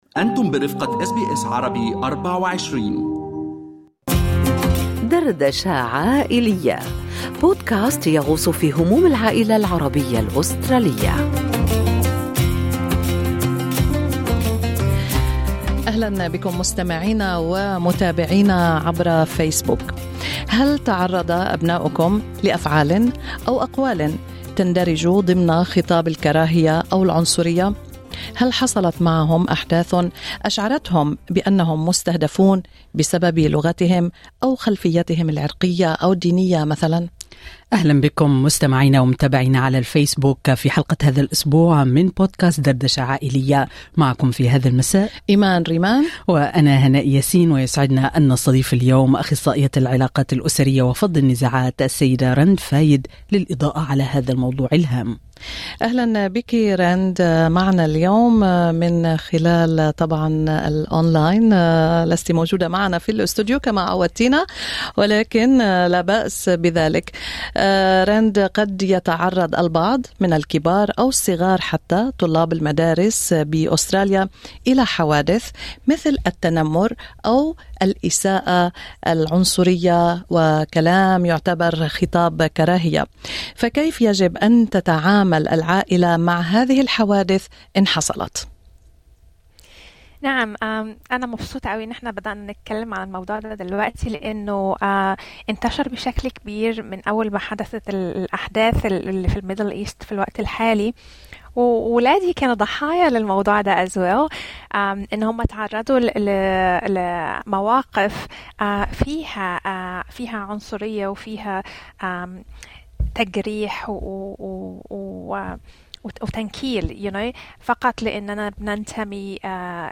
أخصائية العلاقات الأسرية وفض النزاعات